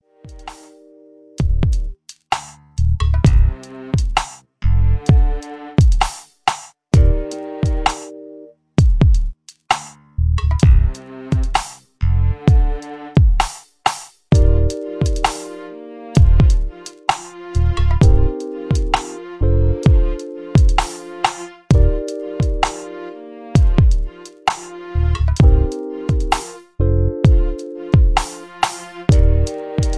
R&B Mid Tempo